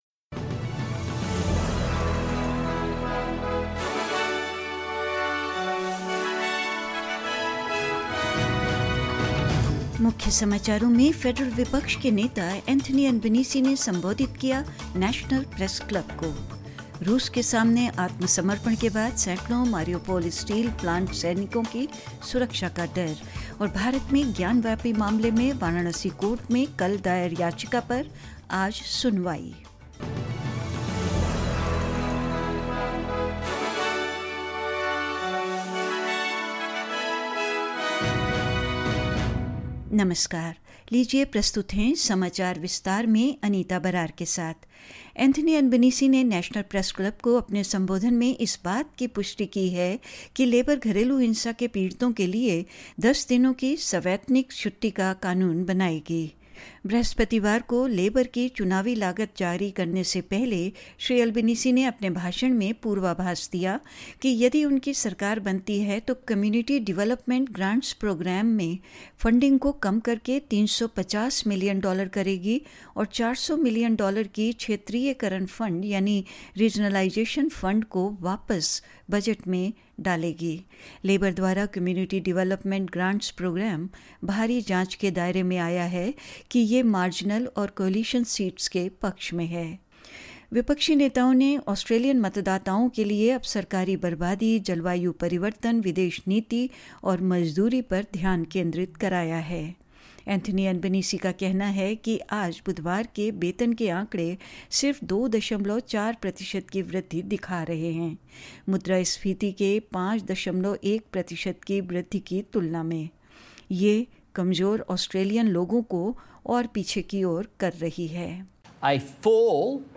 In this latest SBS Hindi bulletin: Federal Opposition leader Anthony Albanese addresses the National Press Club; Fears for the safety of hundreds Mariupol steel plant fighters after an apparent surrender to Russia; Mick Potter to take over from Trent Barrett as Canterbury Bulldogs coach for the rest of the N-R-L season and more news